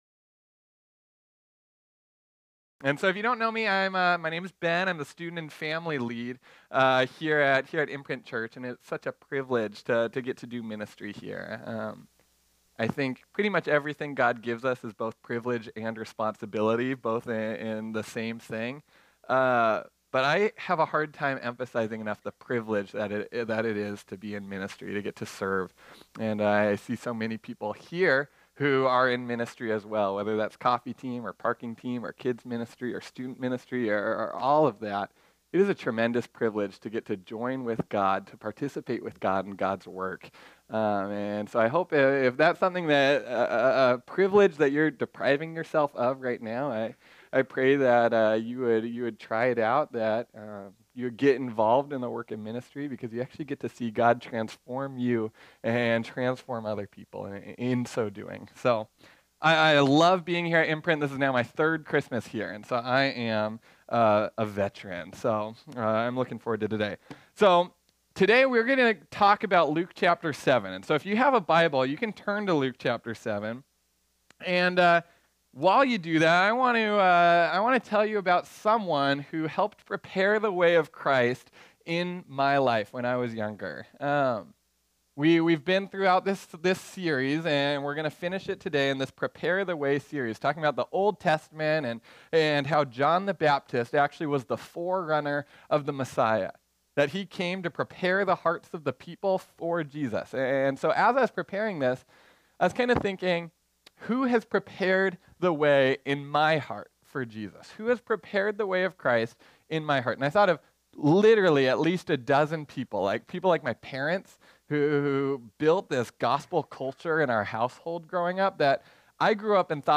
This sermon was originally preached on Sunday, December 30, 2018.